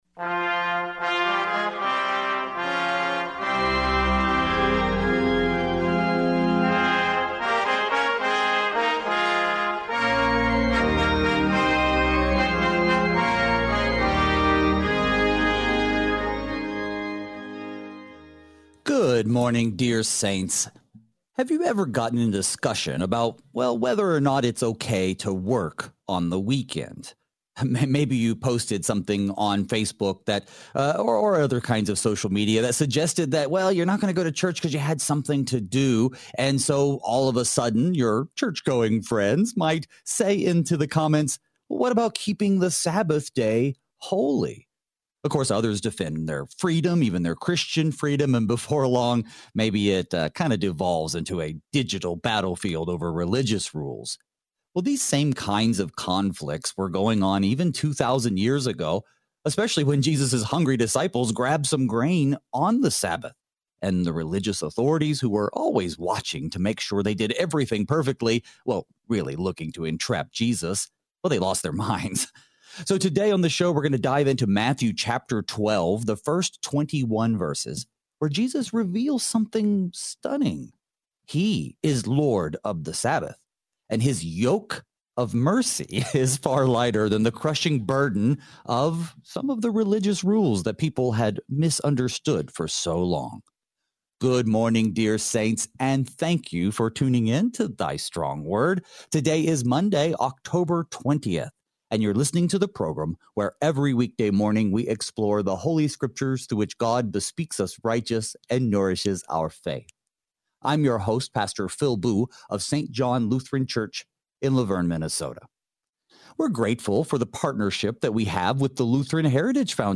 this verse-by-verse study